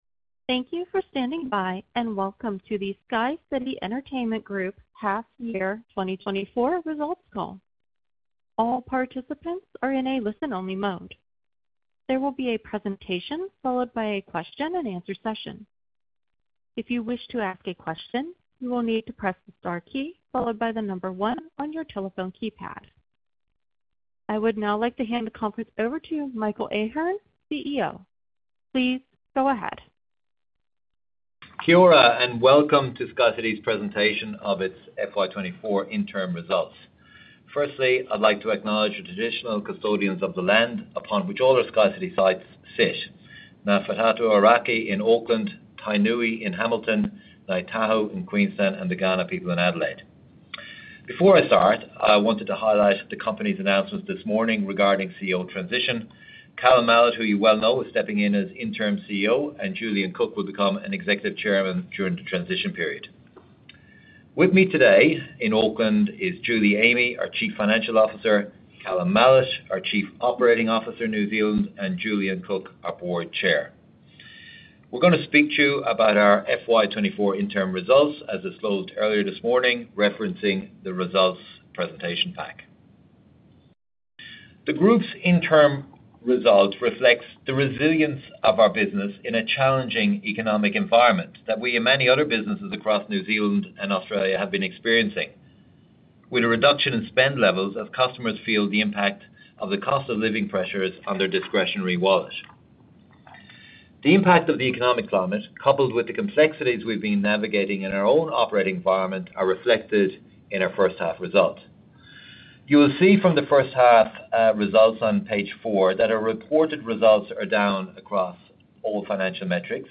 2024-interim-results-conference-call-replay.mp3